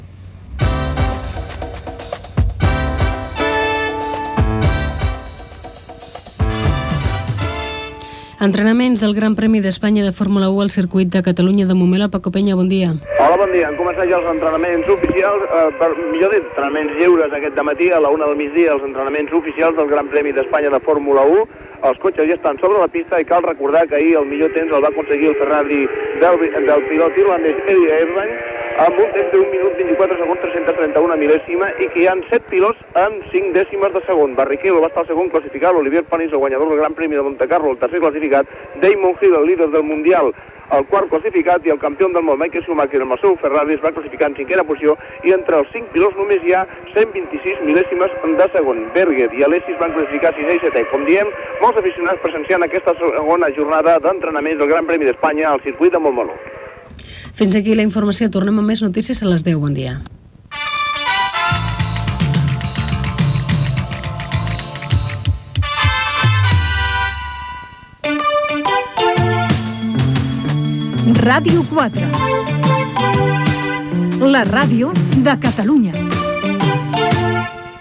Crònica des del Circuit de Catalunya on es disputen els entrenaments lliures del GP d'Espanya de Fórmula 1. Careta de sortida i indicatiu de l'emissora.
Informatiu